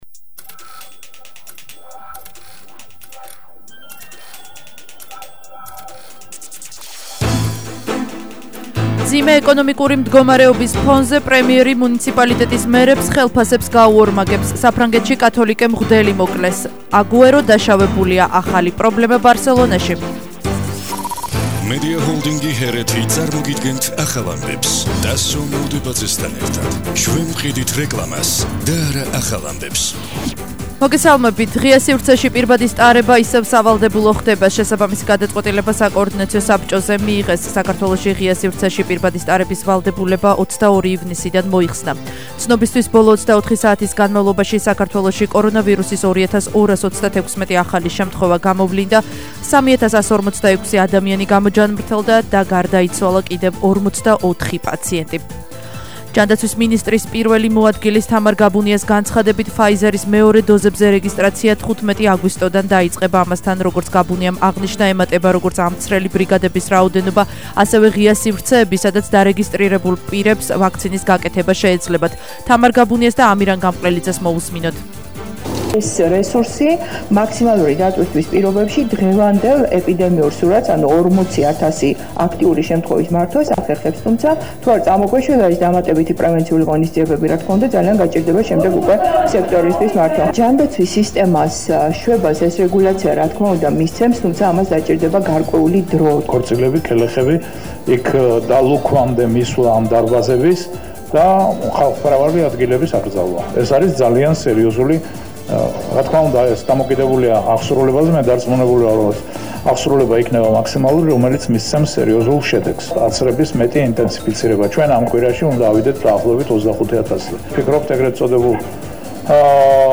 ახალი ამბები 17:00 საათზე –09/08/21